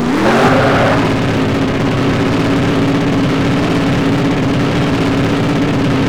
Index of /server/sound/vehicles/lwcars/Detomaso_pantera
rev.wav